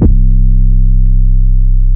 MZ 808 [Plugg #1 - C].wav